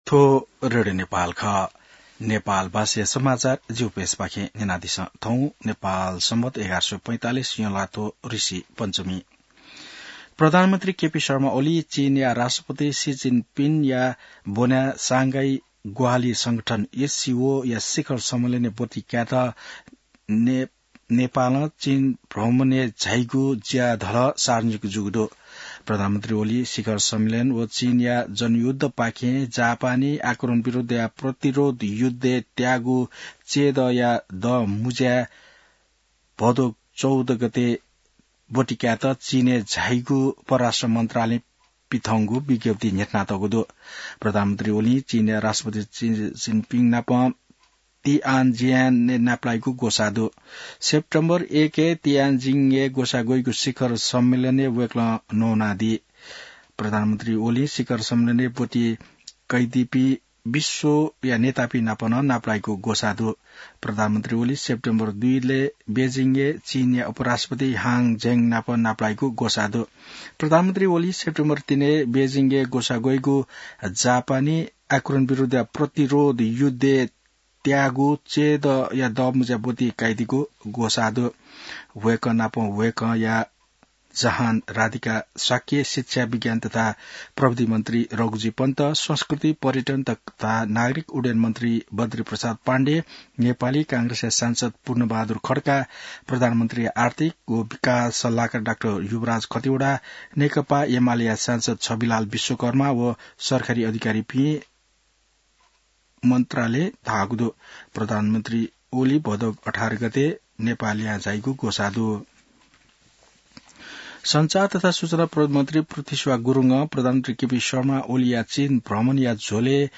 नेपाल भाषामा समाचार : १८ पुष , २०२६